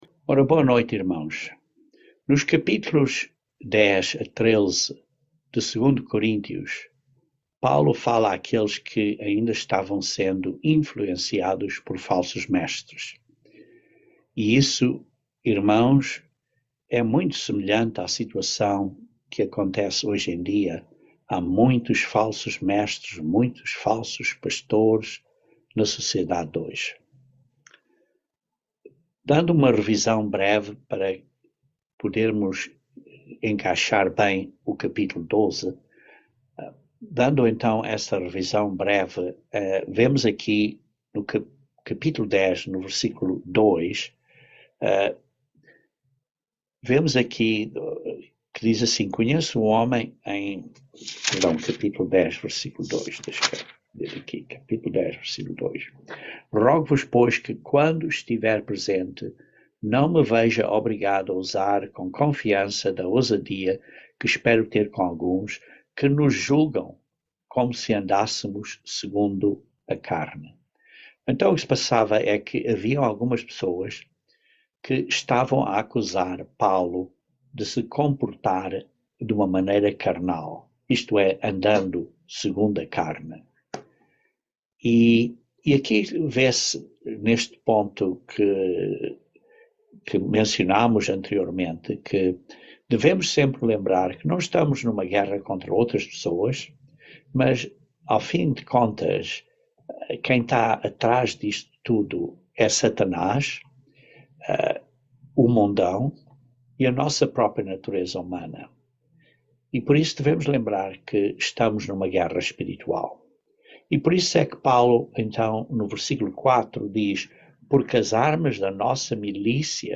Estudo Bíblico
Given in Patos de Minas, MG